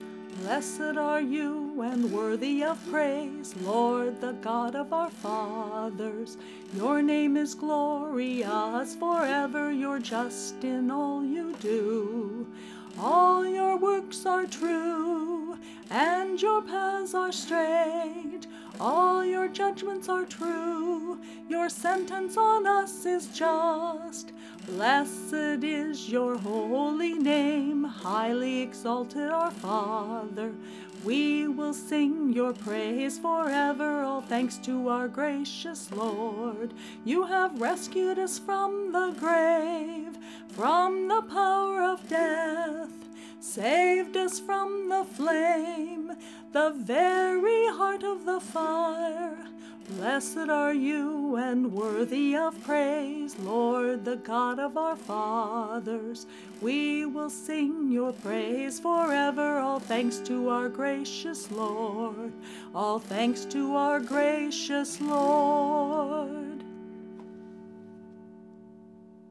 This entry was posted in Christian Music, Uncategorized and tagged , , , , , , , , .